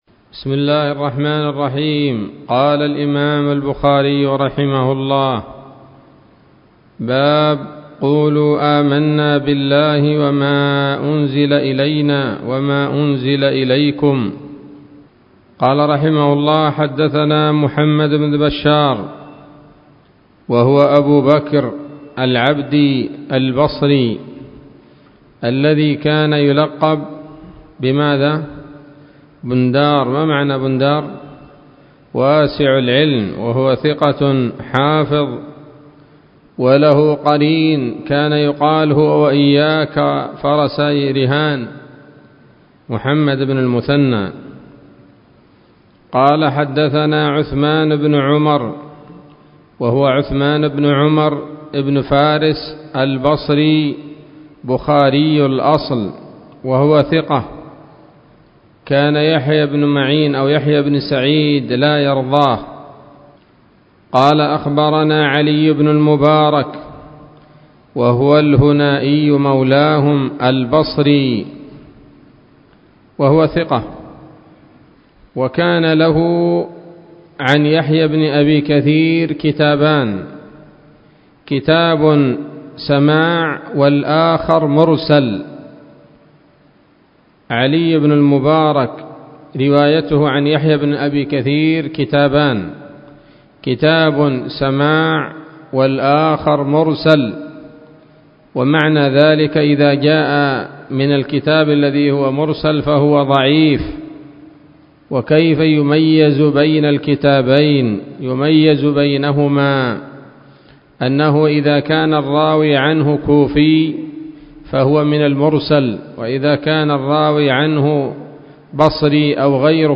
الدرس الثاني عشر من كتاب التفسير من صحيح الإمام البخاري